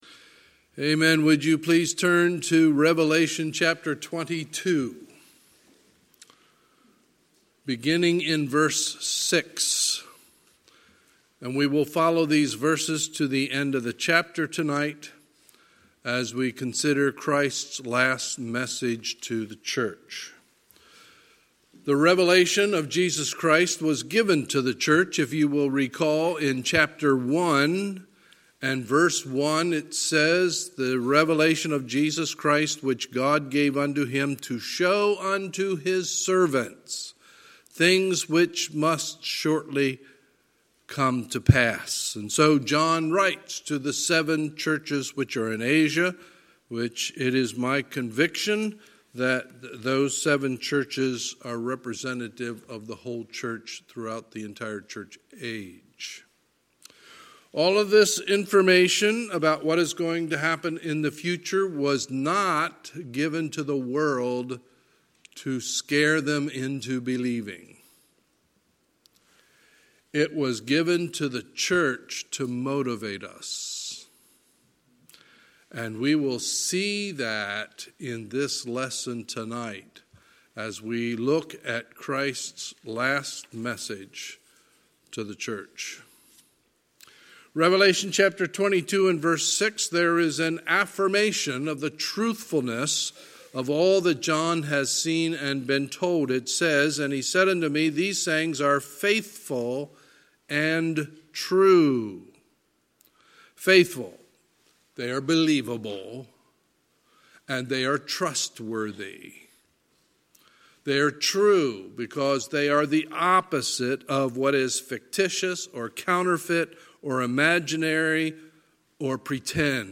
Sunday, December 29, 2019 – Sunday Evening Service
Sermons